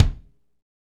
Index of /90_sSampleCDs/Northstar - Drumscapes Roland/KIK_Kicks/KIK_H_H Kicks x
KIK H H K02L.wav